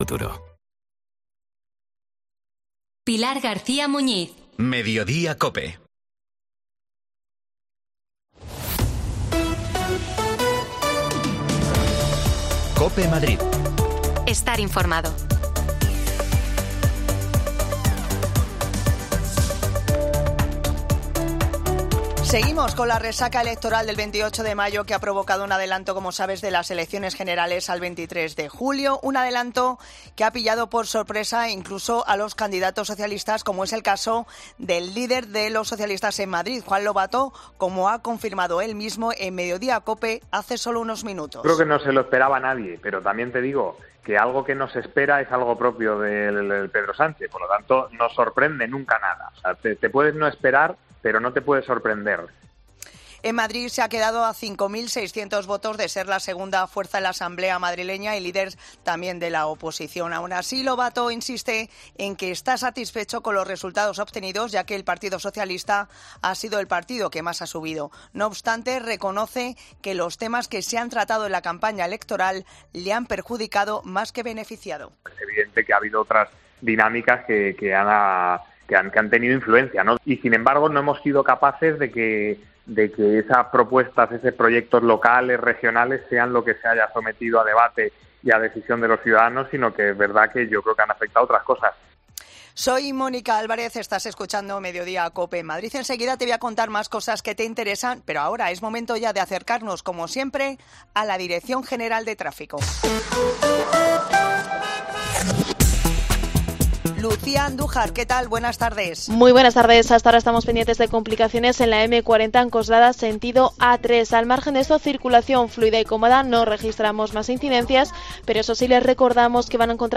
AUDIO: Juan Lobato, líder de los socialistas madrileños, habla en Cope tras los malos resultados de las elecciones del 28 de Mayo